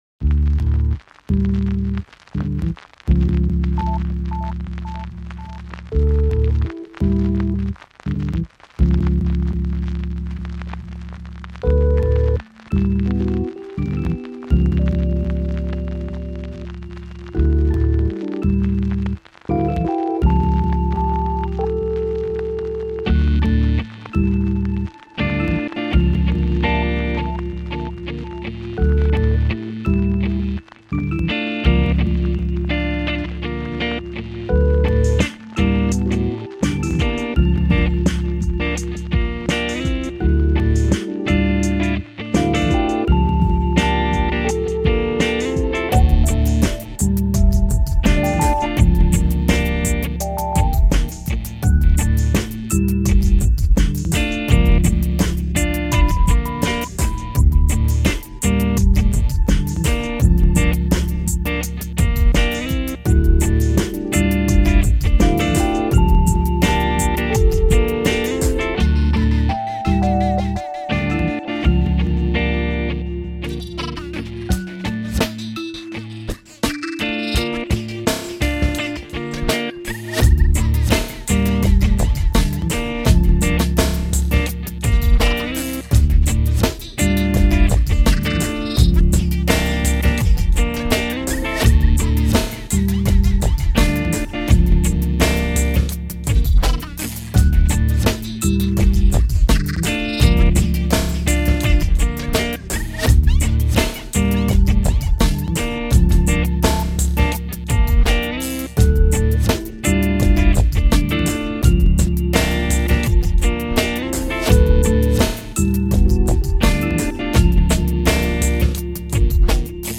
I'm pleased to give to you a total chill trip hop track.
Rhodes Piano and guitar